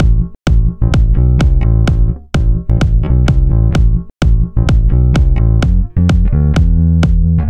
Оба в режиме с примерно 50% сайдчейна.